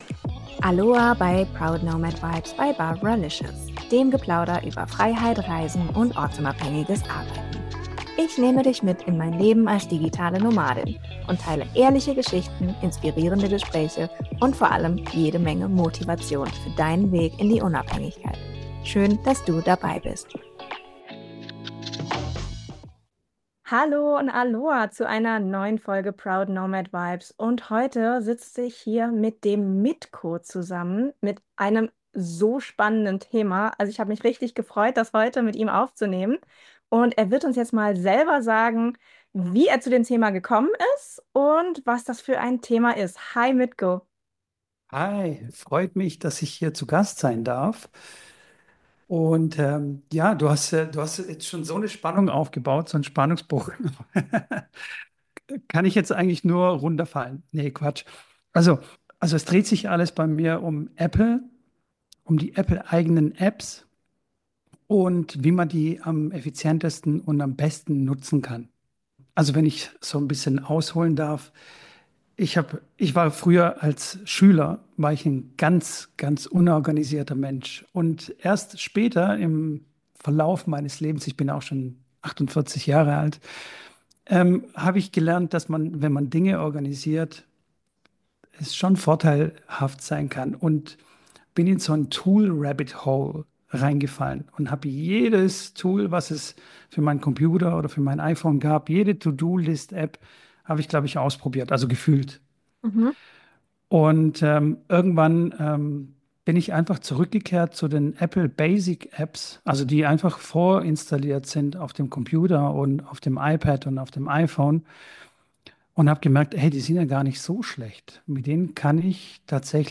Warum das für ihn nicht nur einfacher, sondern auch produktiver ist, erfährst du im Gespräch. Wir sprechen über: - die Suche nach dem passenden Tool – und warum weniger oft mehr ist - wie du deine To-Dos und Ideen so organisierst, dass du nichts mehr vergisst - die Kunst, Informationen wiederzufinden, ohne Chaos zu stiften - mentale Entlastung durch klare Systeme - den Mut, Dinge einfach zu halten – gerade als digitaler Nomade oder Selbstständiger Wenn du auch oft das Gefühl hast, dass Tools mehr Zeit kosten als sparen, ist diese Folge genau richtig für dich!